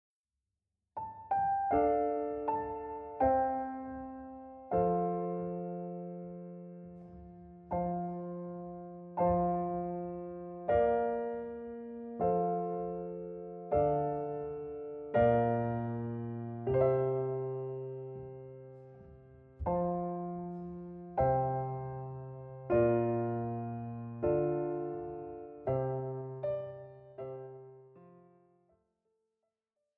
Besetzung: Oboe und Klavier